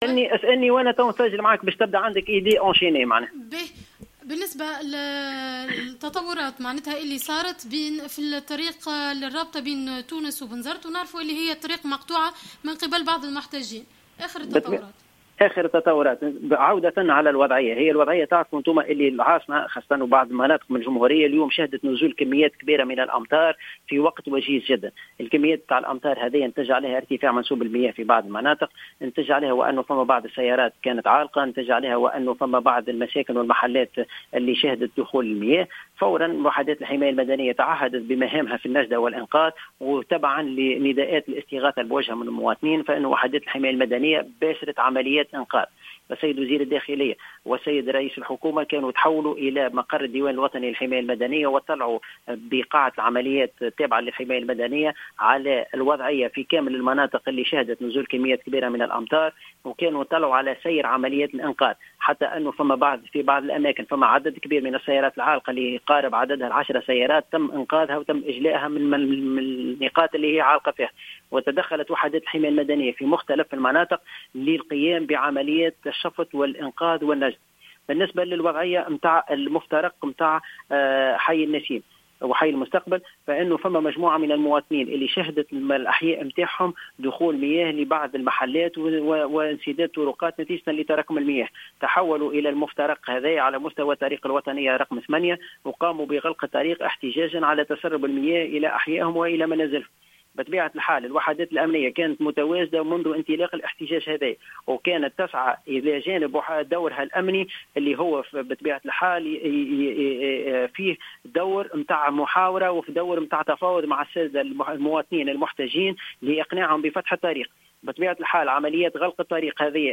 في تصريح هاتفي للجوهرة أف أم